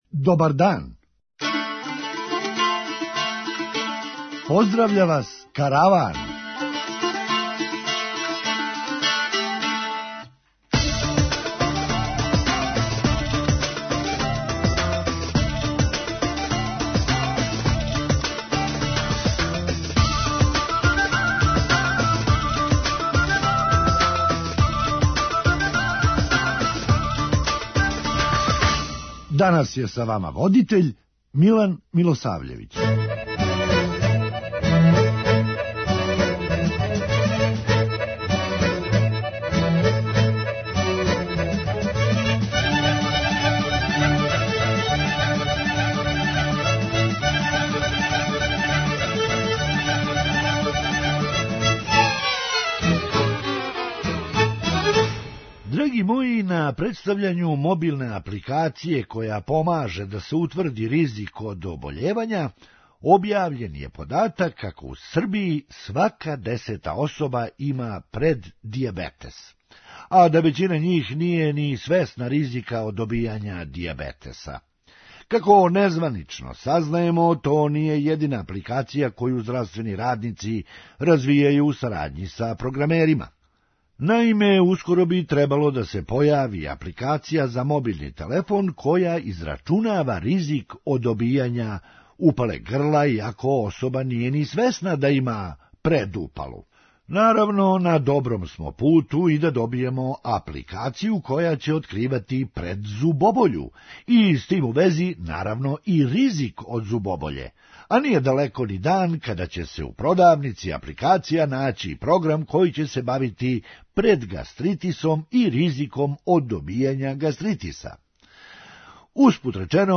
Хумористичка емисија
Да ствар буде гора, против уласка Србије у Европску унију је и већина грађана Европске уније. преузми : 9.20 MB Караван Autor: Забавна редакција Радио Бeограда 1 Караван се креће ка својој дестинацији већ више од 50 година, увек добро натоварен актуелним хумором и изворним народним песмама.